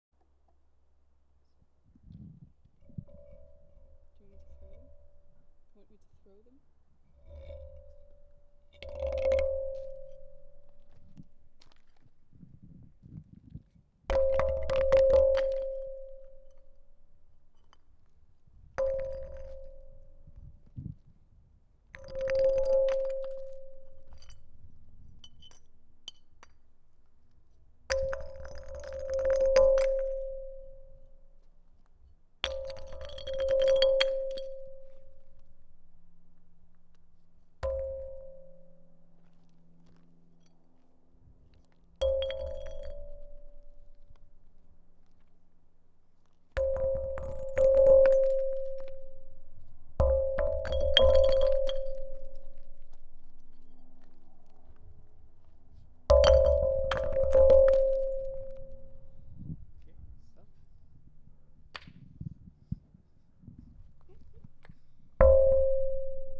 A playfull and experimental first encounter has taken place in the form of body movements, sound recordings and documentation.
Sound recording sample; Ghar Lapsi
ROCKS_36_DUO_ghar-lapsi.mp3